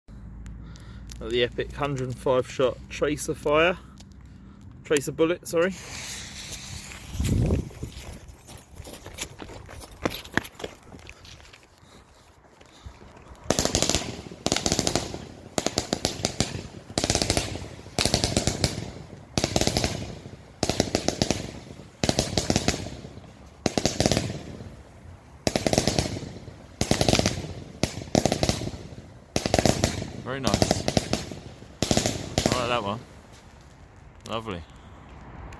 Tracer Bullet 105 Shot Fan Sound Effects Free Download